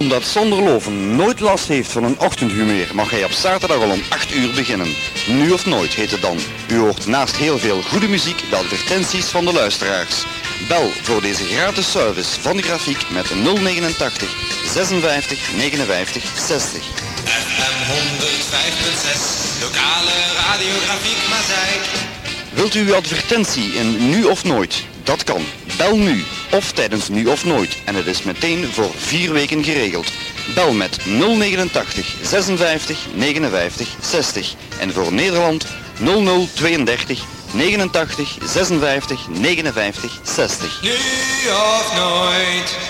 Reclamespot